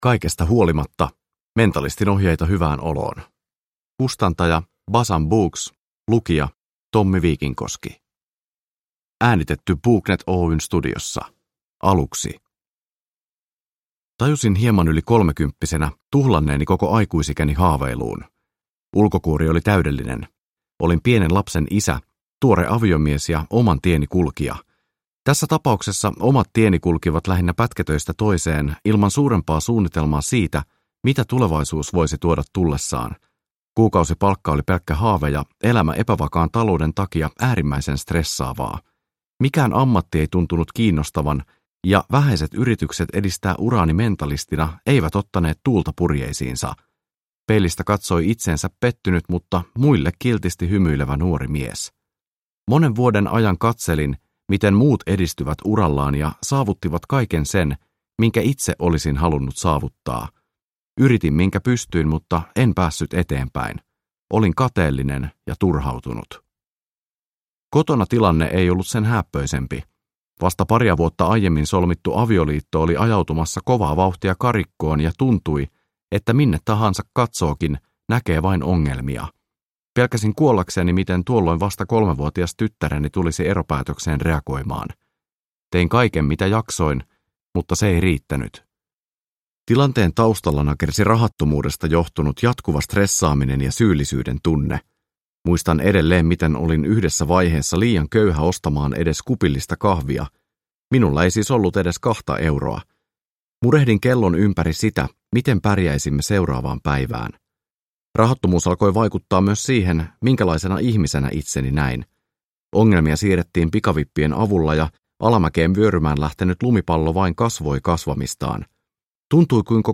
Kaikesta huolimatta – Ljudbok